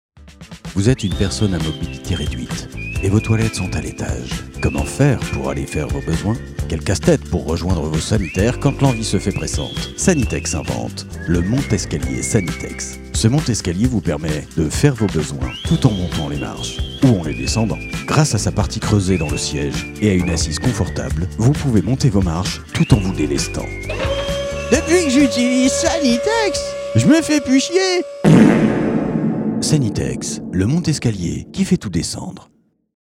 Fausses Pubs RADAR parodies publicités Fausses pubs